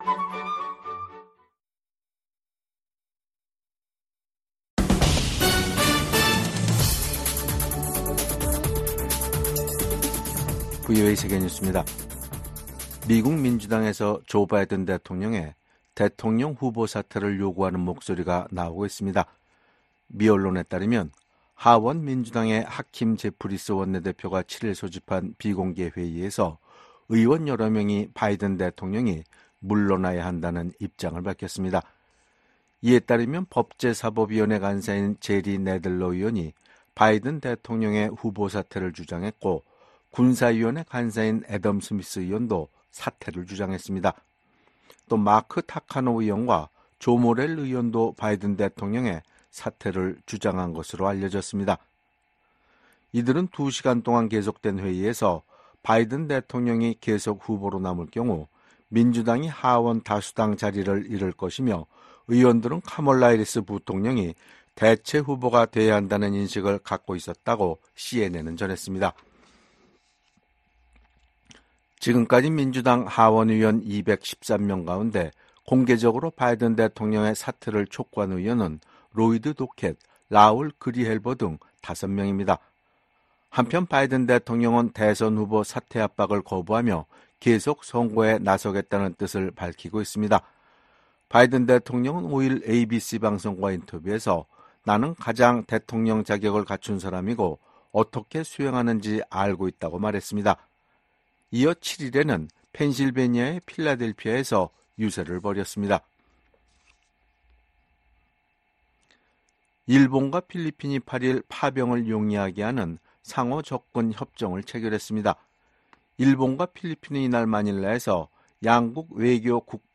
VOA 한국어 간판 뉴스 프로그램 '뉴스 투데이', 2024년 7월 8일 2부 방송입니다. 북한의 핵과 미사일 프로그램이 주변국과 세계안보에 대한 도전이라고 나토 사무총장이 지적했습니다. 윤석열 한국 대통령은 북-러 군사협력이 한반도와 국제사회에 중대한 위협이라며 한-러 관계는 전적으로 러시아에 달려 있다고 말했습니다. 미국 고위관리는 중국의 러시아 지원이 전쟁을 부추기고 있으며, 워싱턴 나토 정상회의에서 이 문제가 중요하게 논의될 것이라고 밝혔습니다.